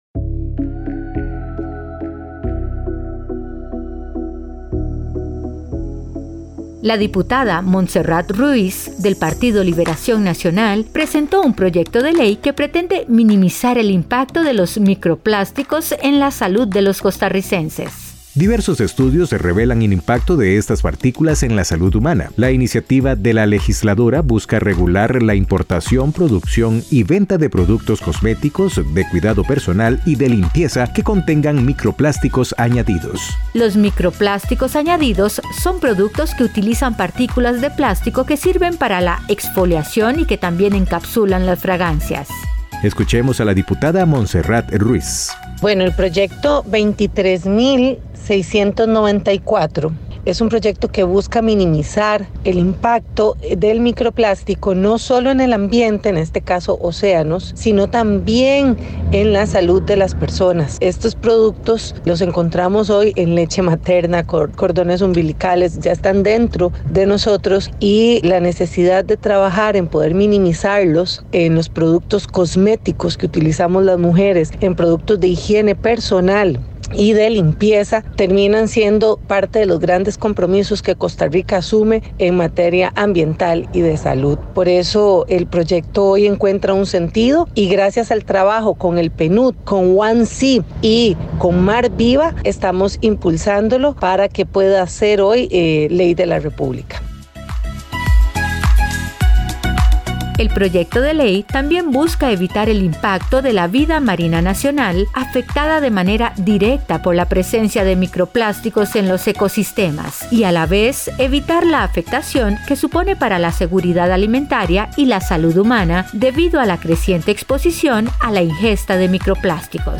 Escuchemos a la diputada Montserrat Ruíz.